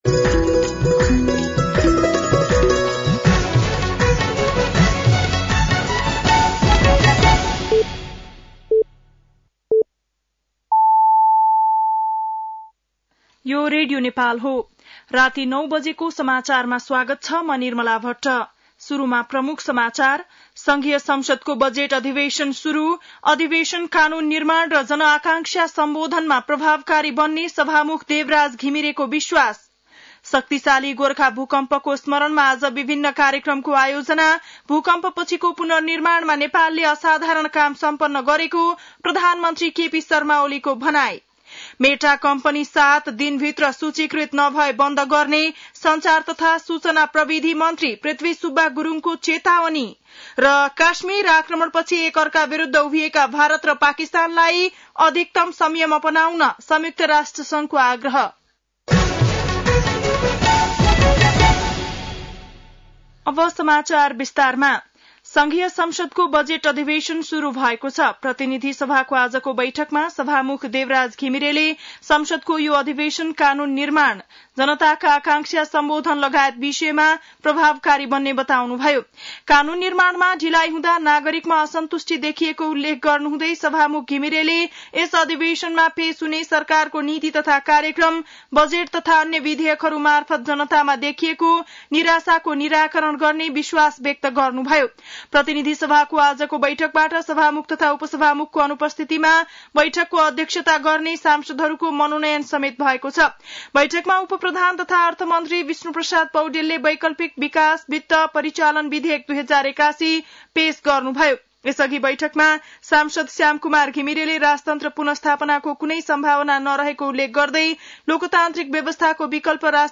बेलुकी ९ बजेको नेपाली समाचार : १२ वैशाख , २०८२